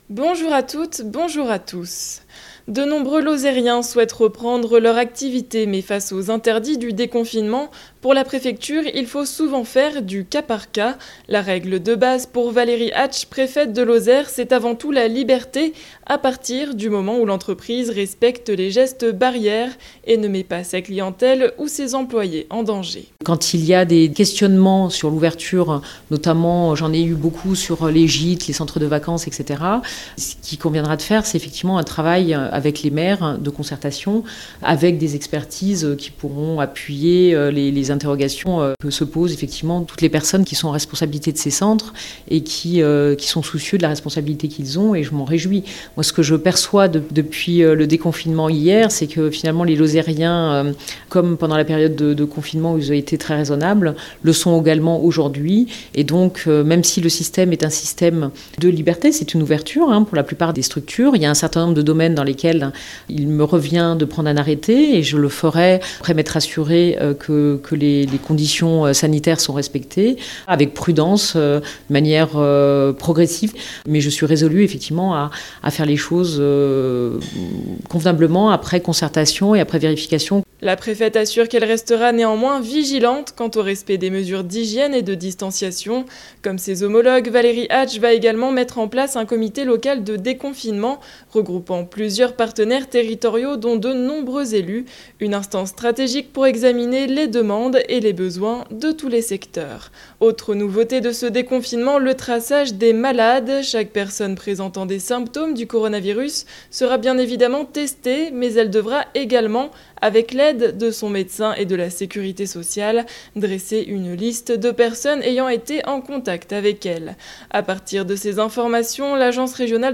Le journal du mercredi 13 mai 2020
• Déconfinement en Lozère : précisions de la préfecture. Ecoutez Valérie Hatsch, préfète de la Lozère